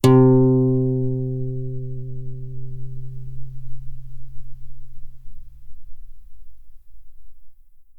Lightningbeam / src / assets / instruments / guitar / acoustic-guitar / samples / C3_ff.mp3
C3_ff.mp3